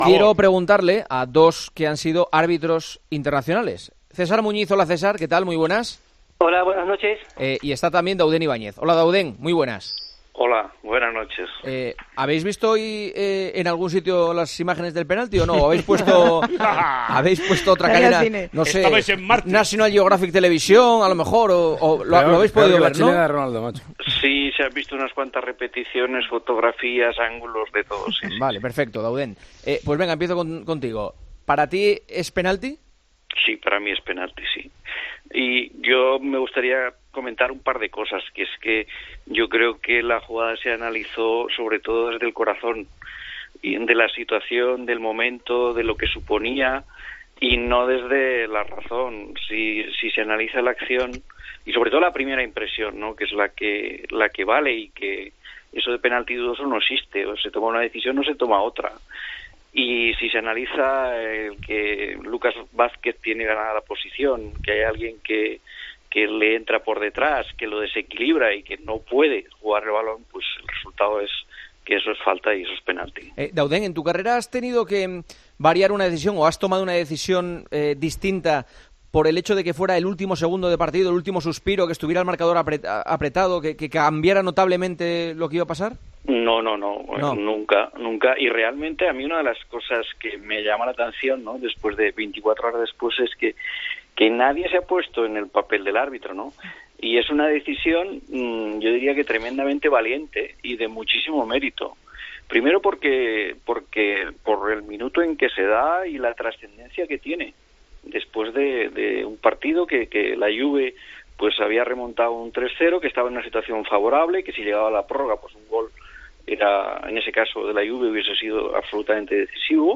AUDIO: El Partidazo de COPE llamó a dos ex colegiados españole spara saber qué habrían pitado ellos en la jugada de la polémica en el Real Madrid - Juventus,...